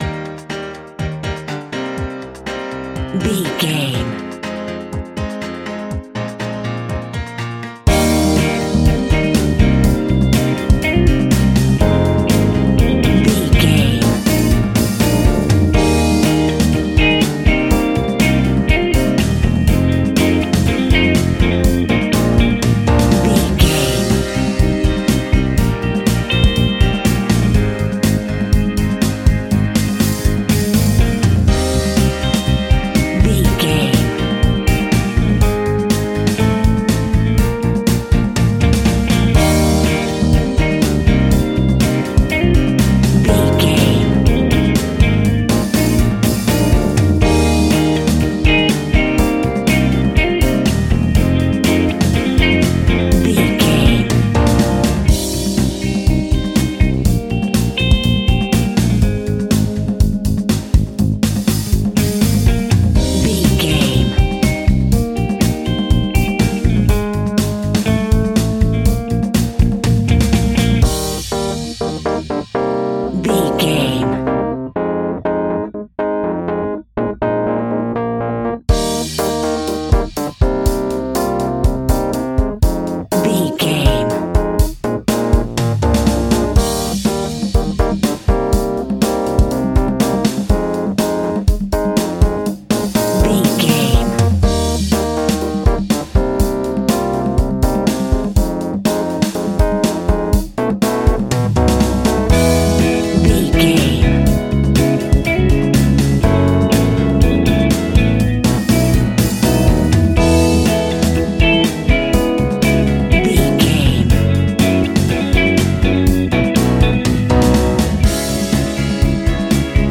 Ionian/Major
pop
pop rock
synth pop
happy
upbeat
bouncy
drums
bass guitar
electric guitar
hammond organ
acoustic guitar